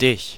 Ääntäminen
Ääntäminen Tuntematon aksentti: IPA: /dɪç/ Haettu sana löytyi näillä lähdekielillä: saksa Käännös Ääninäyte Pronominit 1. accusative US 2. yourself US 3. thee US 4. you Suku: c .